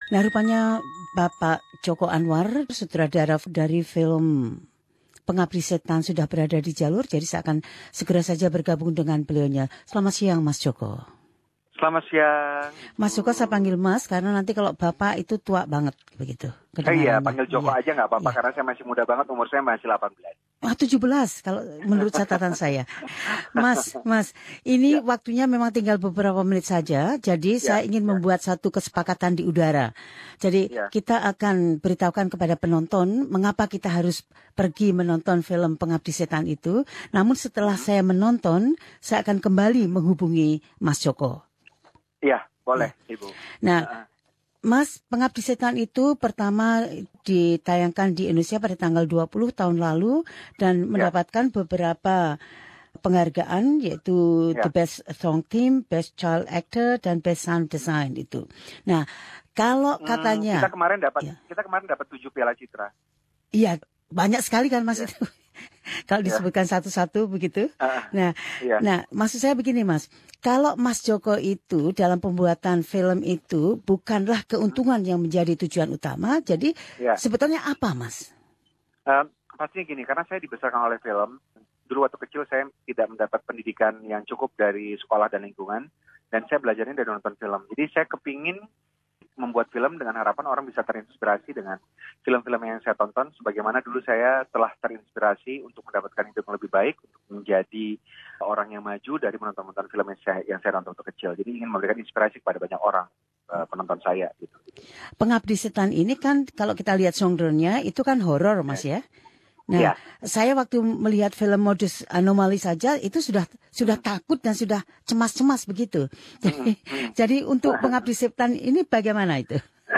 Sutradara Joko Anwar berbicara tentang “Pengabdi Setan”, filmnya yang mendapatkan pujian serta penghargaan, yang akan membuka Festival Film Indonesia 2018 di Melbourne.